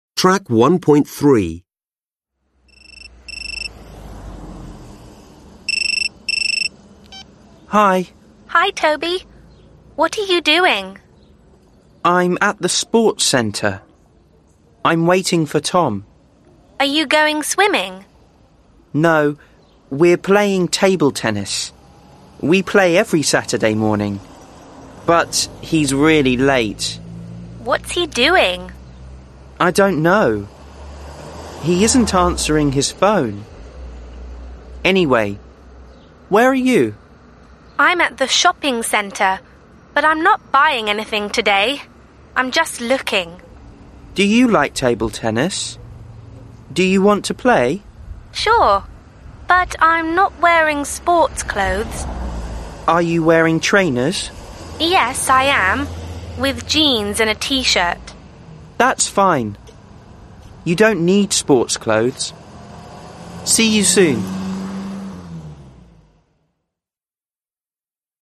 6 (trang 7 Tiếng Anh 10 Friends Global) Complete the phone dialogue. Use the correct present simple or present continuous form of the verbs in brackets.